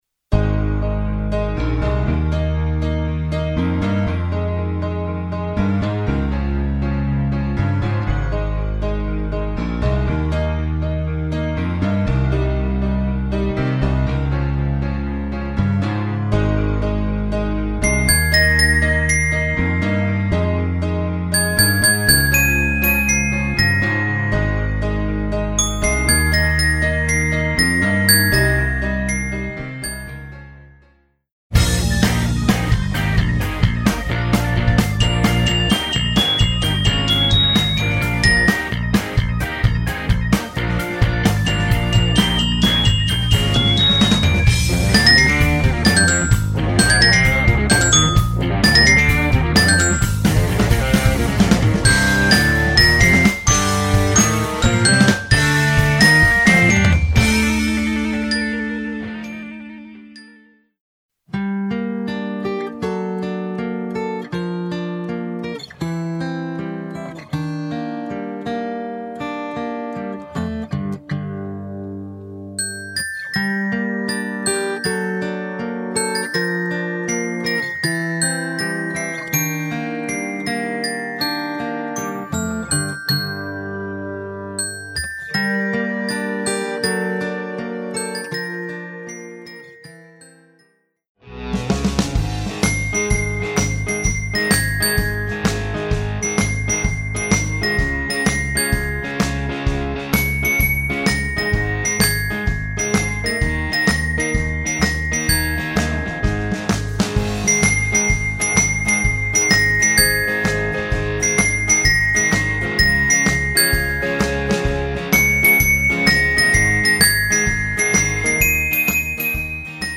Voicing: Mallet